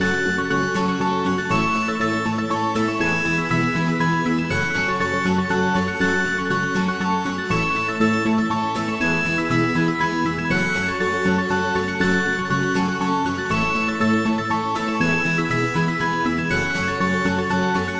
Short medieval hardihar loop